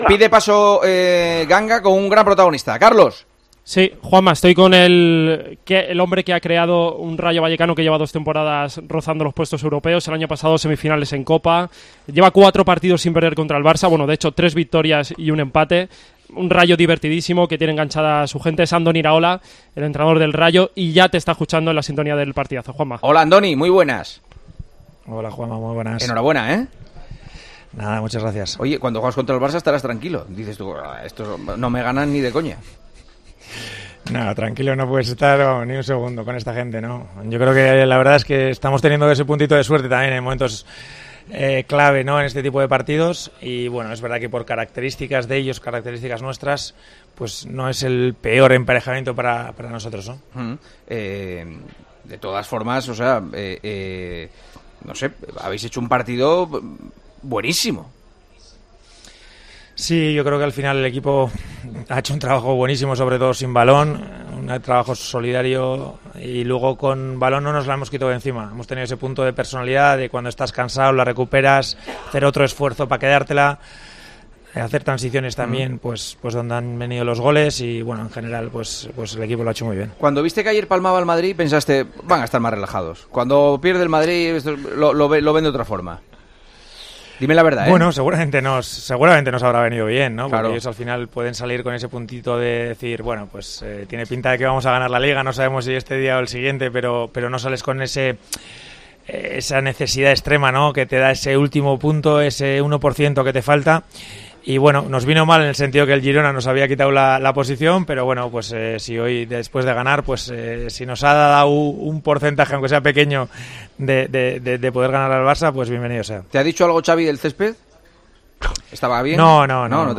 El entrenador del Rayo habló en El Partidazo de COPE sobre su renovación una vez que ha conseguido el objetivo de la permanencia para el conjunto vallecano.
Al término del encuentro, el entrenador rayista, Andorni Iraola, se pasó por los micrófonos de El Partidazo de COPE donde habló de su posible renovación: "En cuanto pasen estos partidos me reuniré con el club y tomaremos las decisiones que haya que tomar".